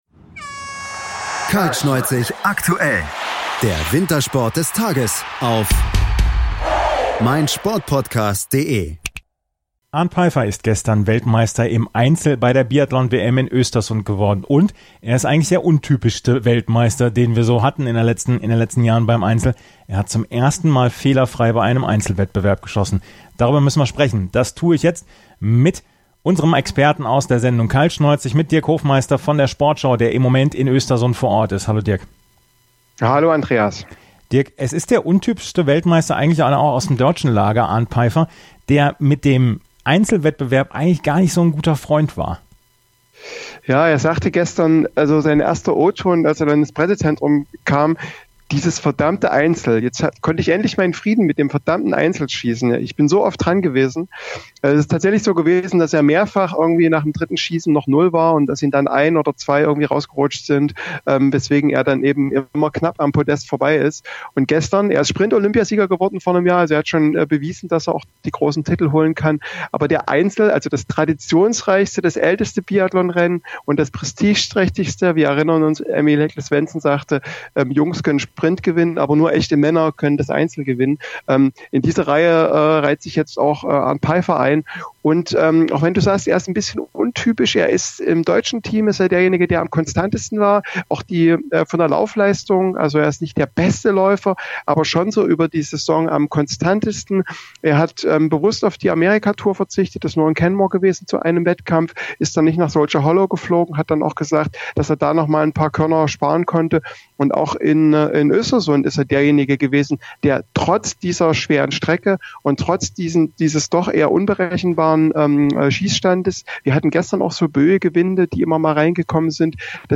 Außerdem haben wir die O-Töne von Arnd Peiffer, Benedikt Doll und vom ZDF-Experten Sven Fischer.